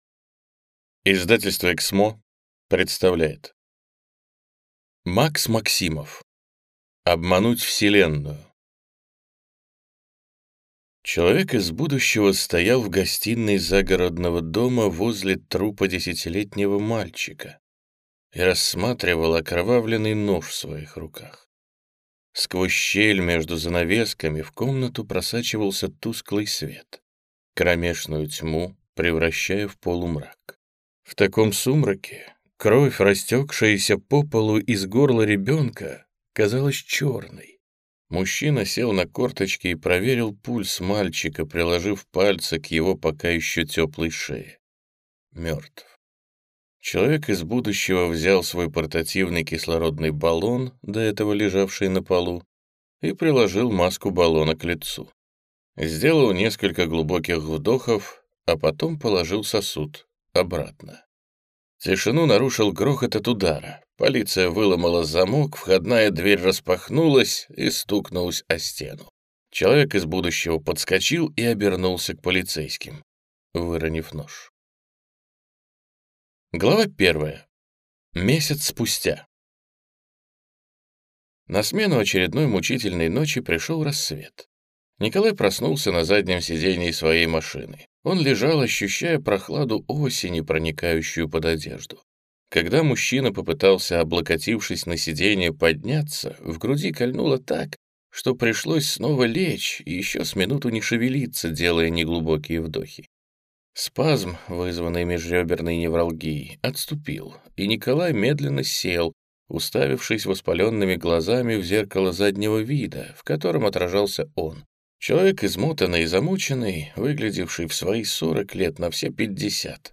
Аудиокнига Обмануть вселенную | Библиотека аудиокниг
Прослушать и бесплатно скачать фрагмент аудиокниги